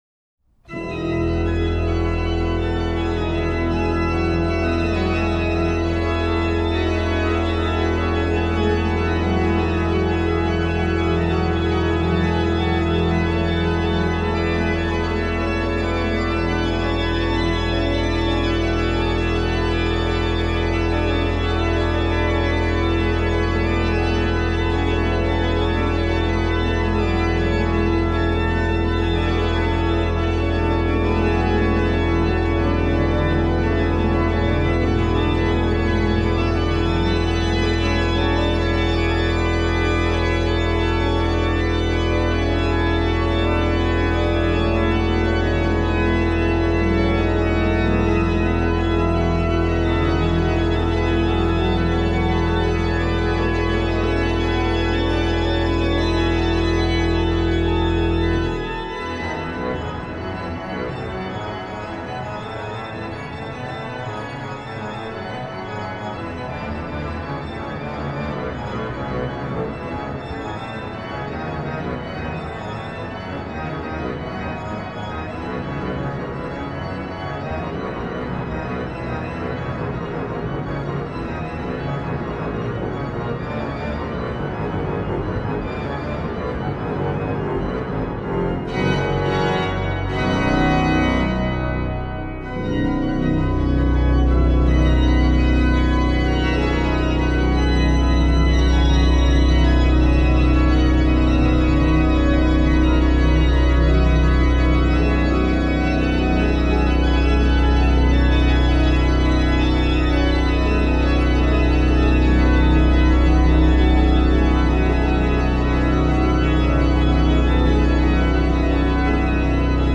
HW: Pr8, Rfl8, Oct4, Oct2, Zim, OW/HW
OW: Pr8, Oct4, Oct2, Mix
Ped: Pr16, Oct8, Oct4, Pos16
m. 55: Ped: +Mix, Tr8